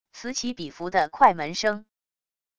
此起彼伏的快门声wav音频